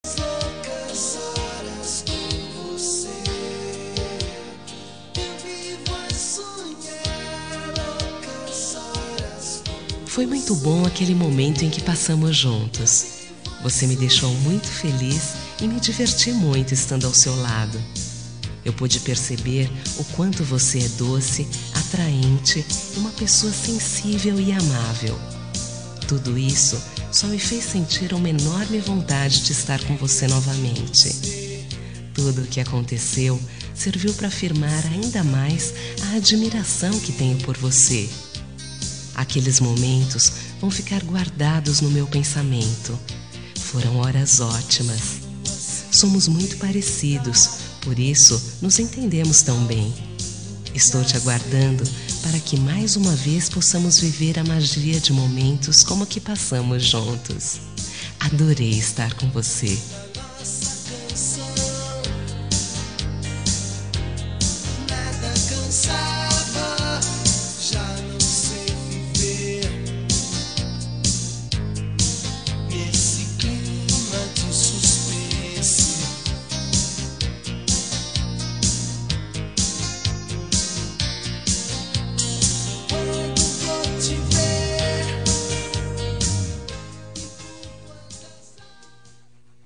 Telemensagem Momentos Especiais – Voz Feminina – Cód: 201877 – Adorei te Conhecer